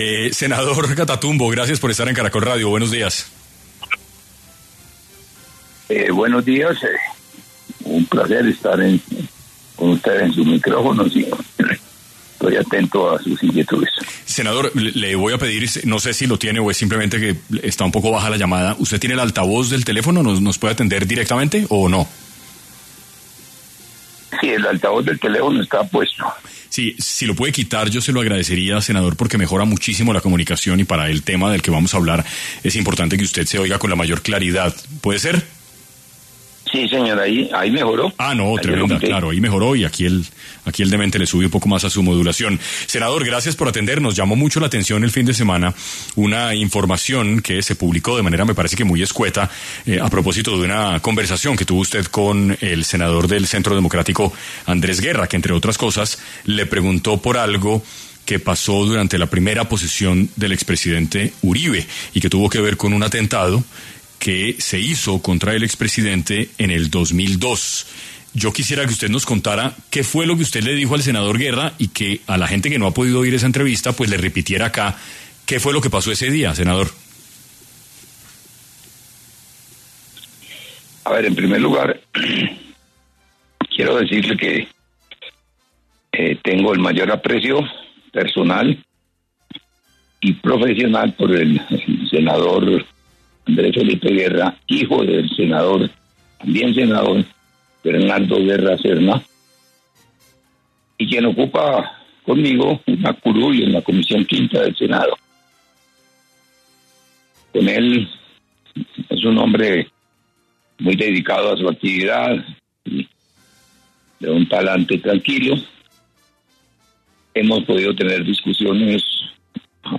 En 6AM de Caracol Radio estuvo Pablo Catatumbo, senador del partido de Comunes, quien habló sobre el atentado que realizó las Farc contra el expresidente Álvaro Uribe durante su posesión en 2002